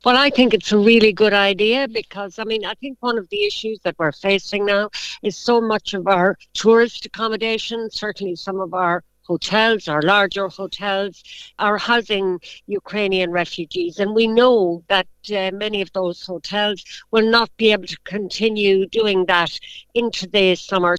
Deputy Harkin says an initiative like this is the right idea to help house people: